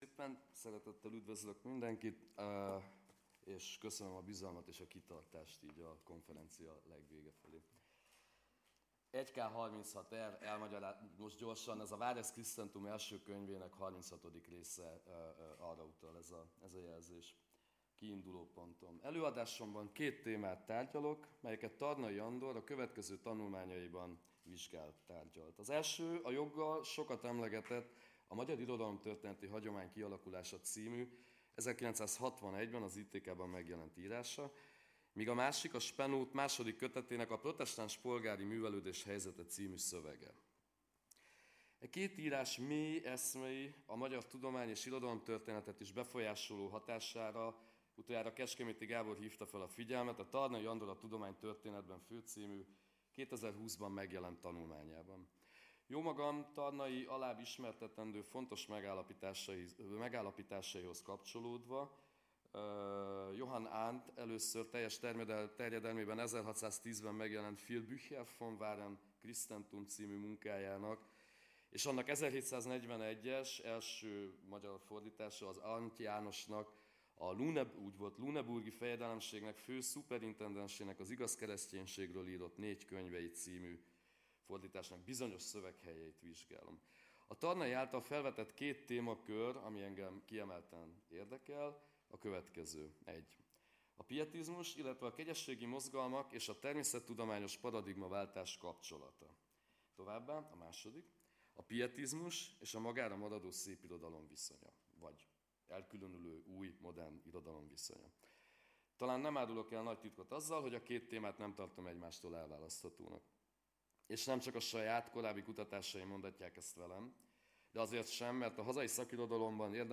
(lecturer)